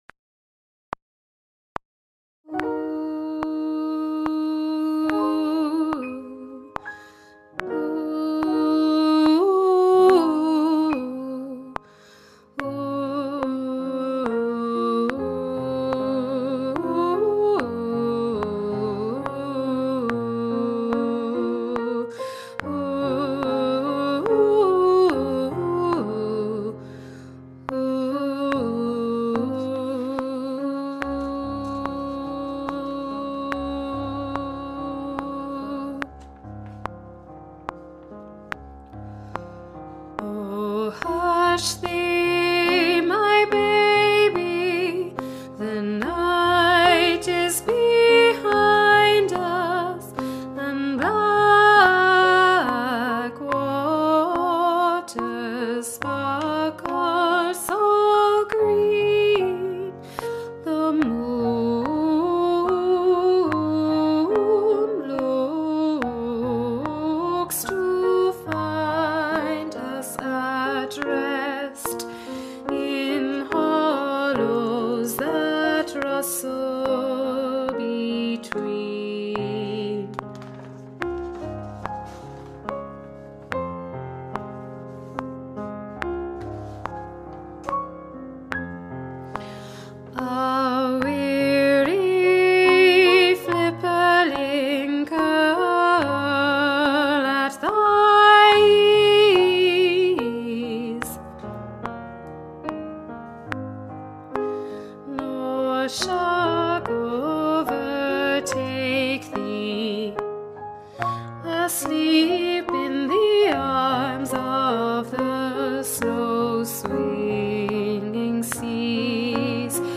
- Œuvre pour chœur à 5 voix mixtes (SATBB) + piano
ALTO Chante